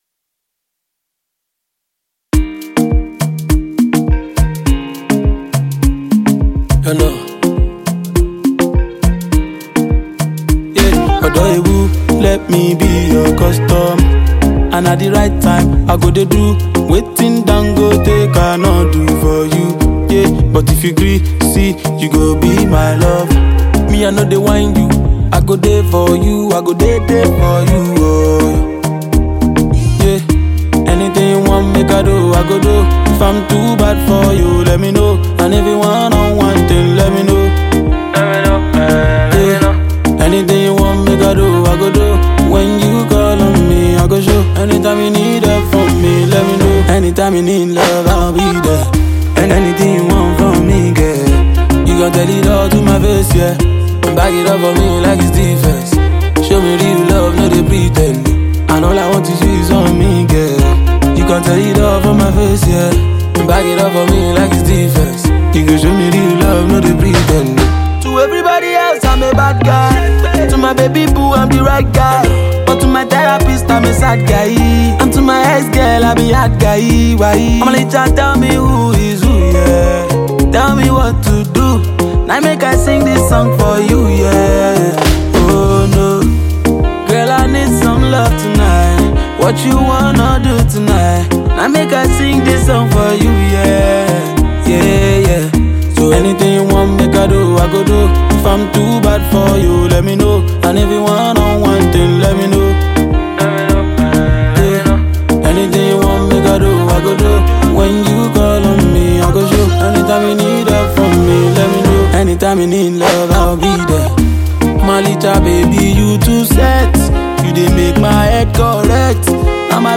A-List Nigerian singer and songwriter